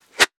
weapon_bullet_flyby_23.wav